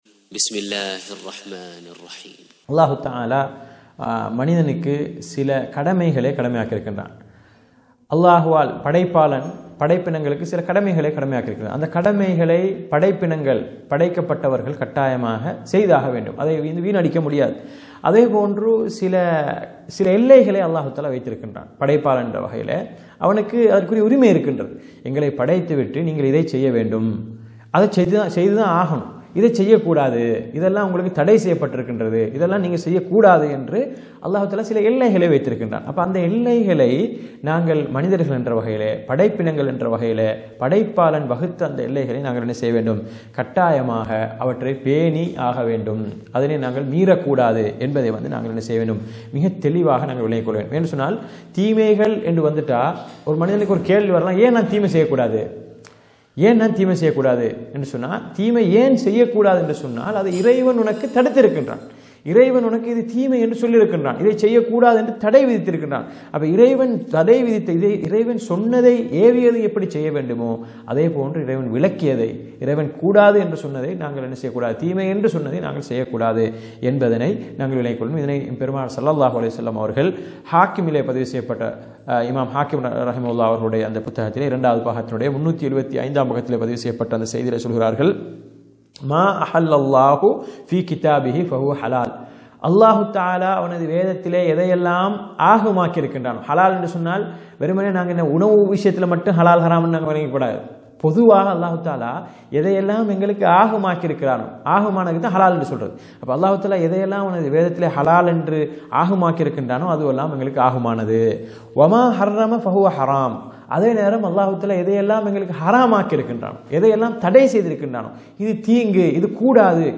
அஷ்ஷைக் முஹம்மது ஸாலிஹ் அல்-முனஜ்ஜித் அவர்களால் எழுதப்பட்ட ‘மக்களின் பார்வையில் சாதாரணமாகிவிட்ட தீமைகள் என்ற நூலின் விளக்க உரையிலிருந்து…